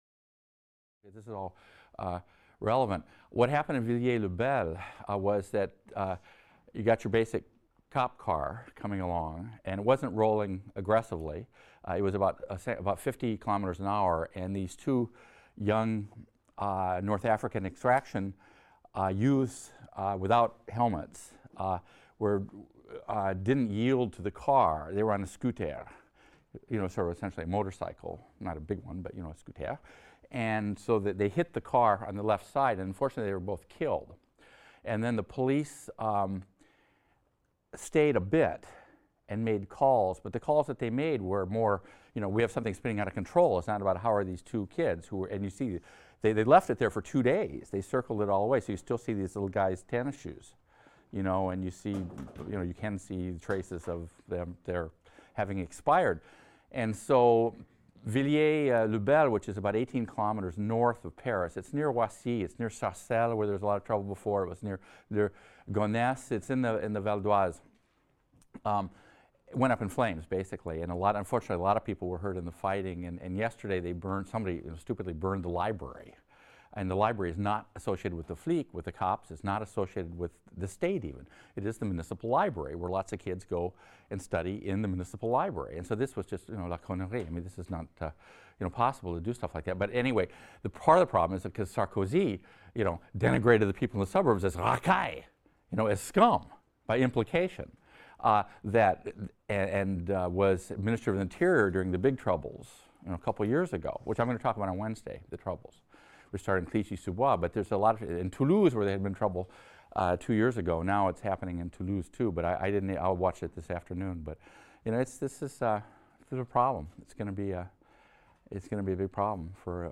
HIST 276 - Lecture 22 - Charles De Gaulle | Open Yale Courses